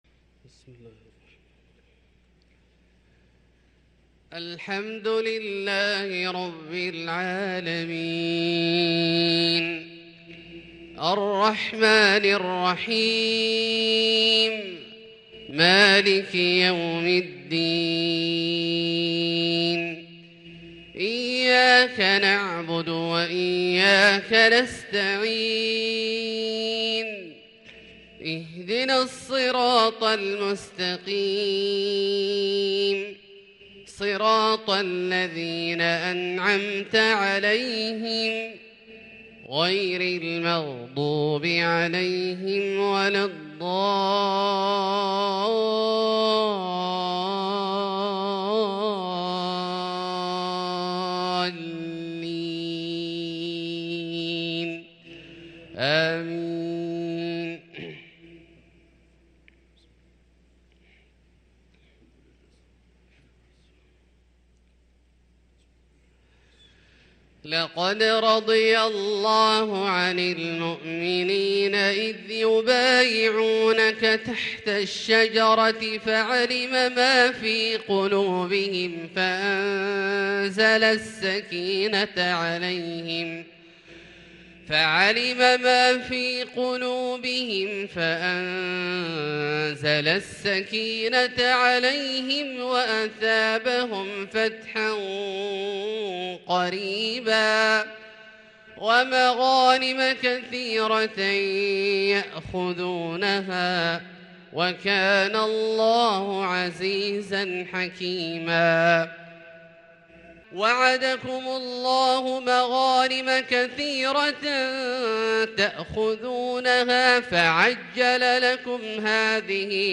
صلاة الفجر للقارئ عبدالله الجهني 19 ذو الحجة 1443 هـ
تِلَاوَات الْحَرَمَيْن .